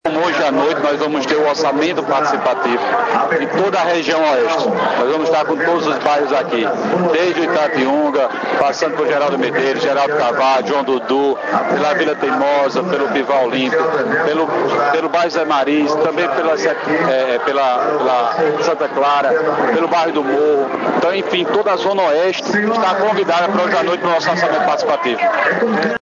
Prefeito Dinaldinho convida população para participar do Orçamento Participativo –